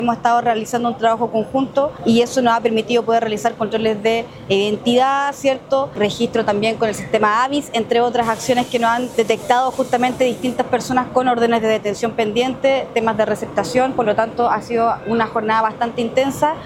La delegada presidencial de Tarapacá, Ivonne Donoso, destacó el resultado de la intervención realizada en la calle Esmeralda, entre Amunátegui y Juan Martínez, sector con alta presencia de migrantes.